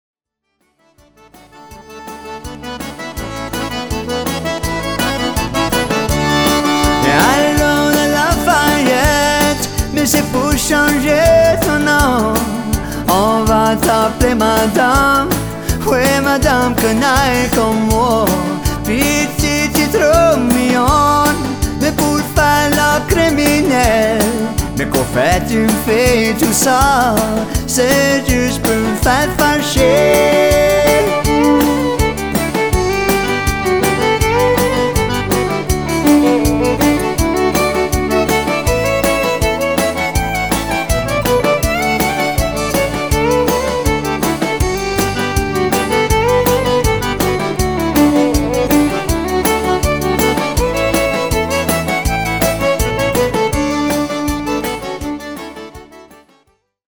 accordions and vocals and acoustic guitar
bass
petite fer